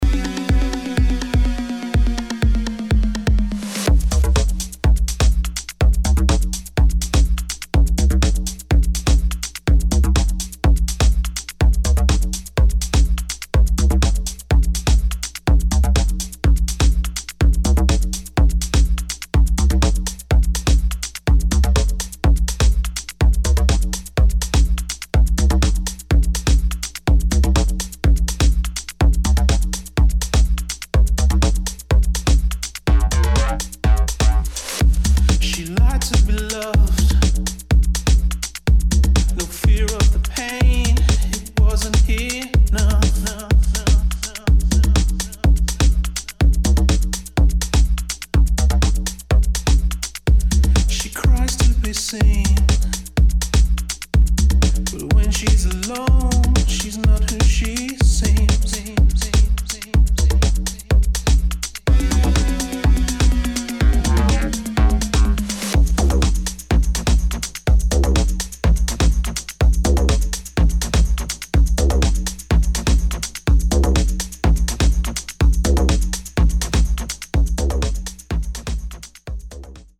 [ BASS ]
DUBSTEP | BASS